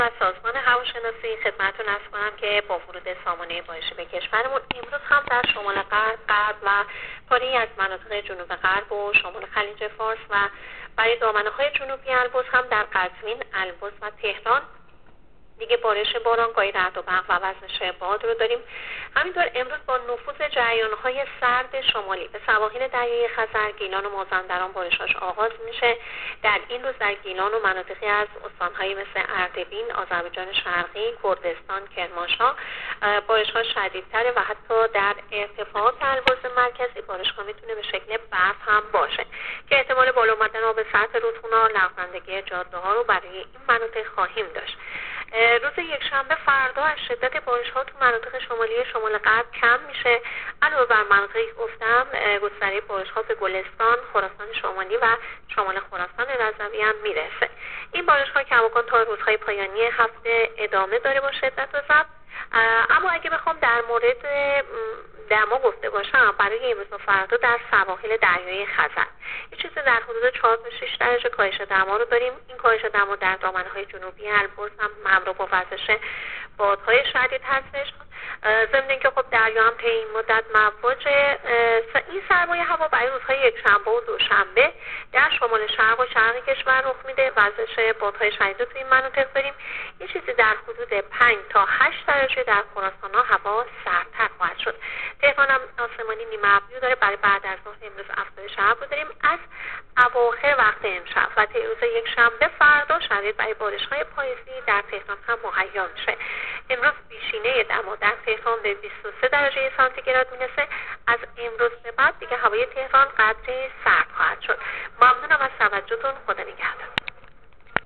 گزارش رادیو اینترنتی از آخرین وضعیت آب‌‌و‌‌‌هوای ۱۷ آبان